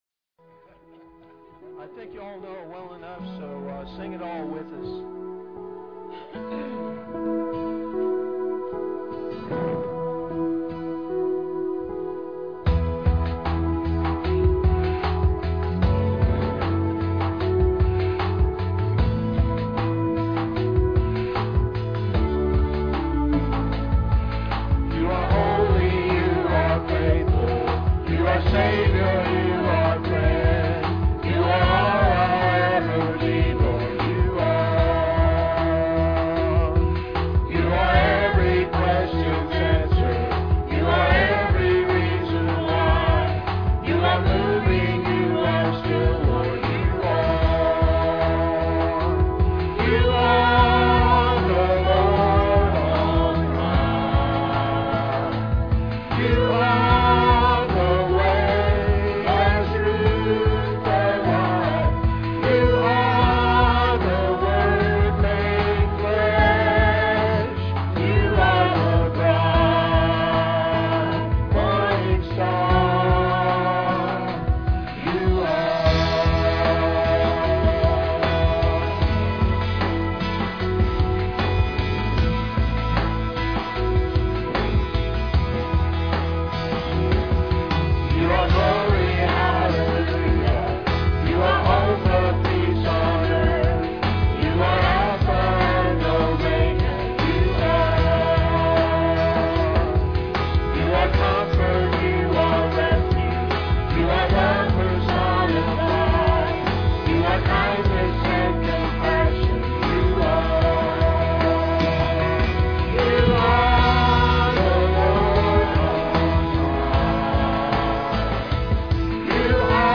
PLAY Prophecy Series, Part 5, Oct 8, 2006 Scripture: Matthew 24:45-25:13. Scripture Reading